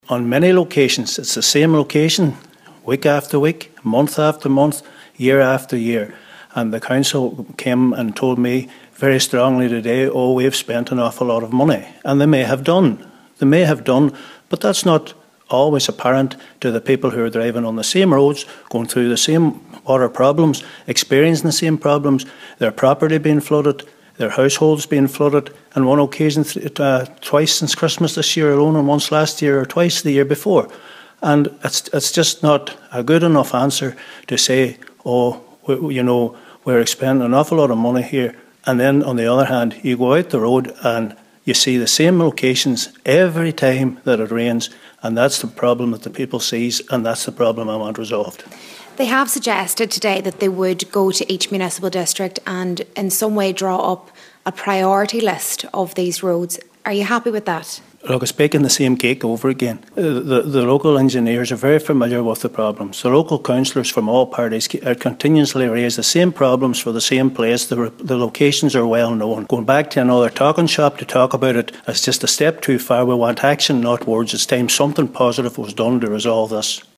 Cllr. Gerry Crawford says such roads have long been identified with extensive flooding easily preventable but more action is needed: